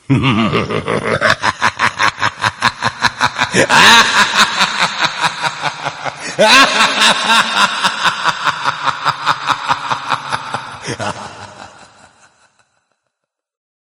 COMEDY - LAUGH 01
Category: Sound FX   Right: Commercial